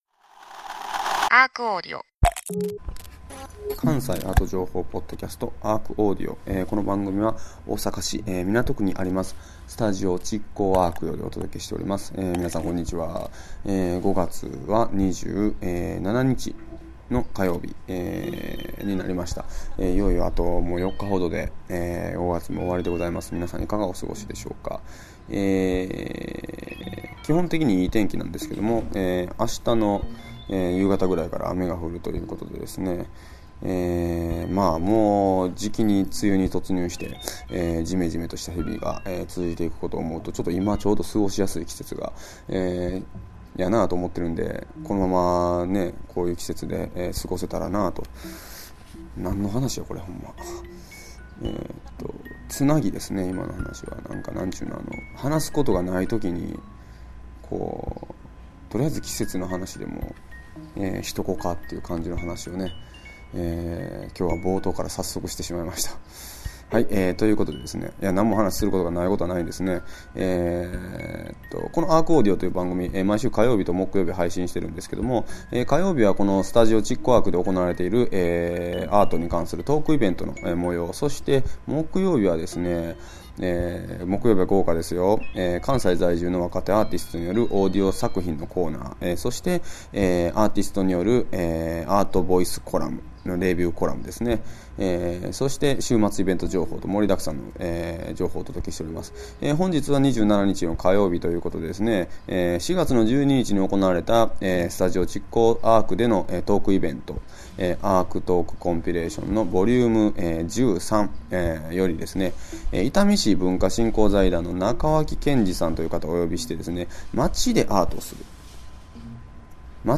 毎週火曜日は築港ARCにて毎月開催されるアートと社会を繋ぐトークイベント「ARCトークコンピレーション」の模様を全4回に分けてお届けします。